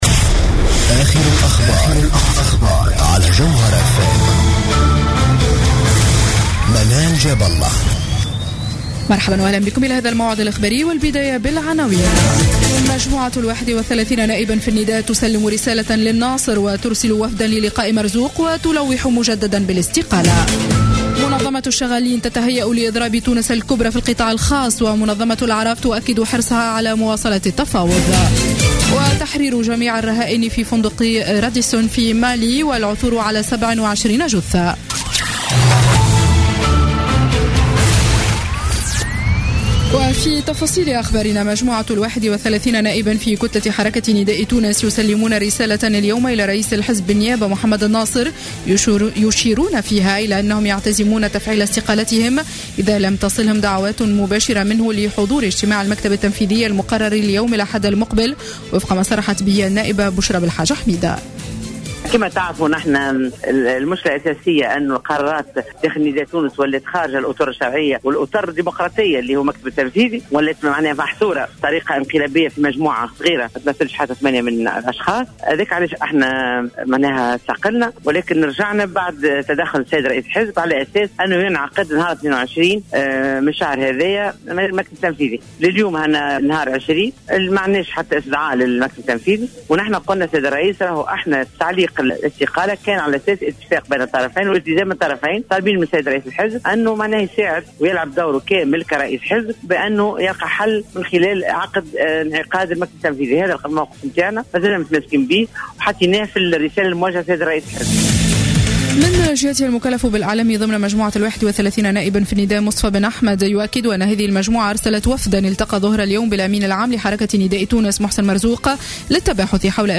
نشرة أخبار السابعة مساء ليوم الجمعة 20 نوفمبر 2015